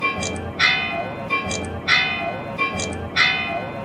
3) ¡Escucha! Este es el sonido de campanas de un cuarto; cuando sean "y media", sonarán dos cuartos ; y para "menos cuarto" sonará tres veces.